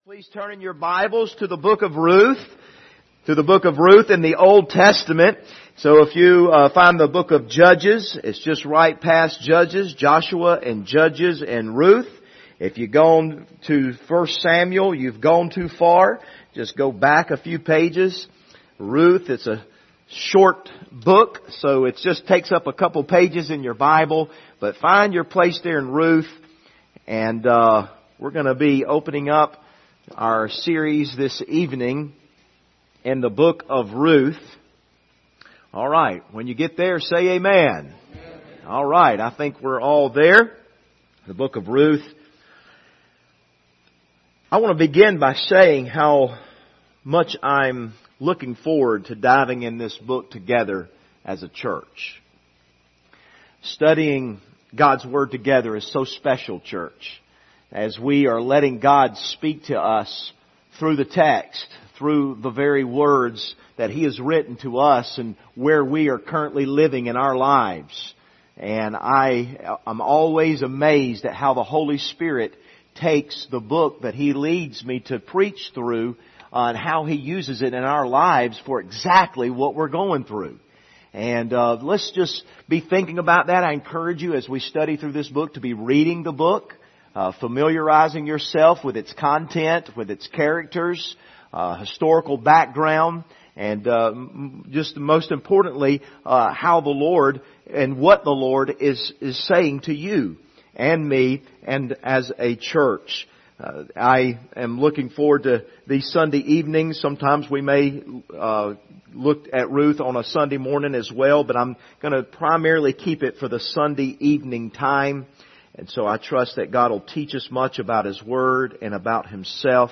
Service Type: Sunday Evening Topics: grace , redemption , salvation « Grounded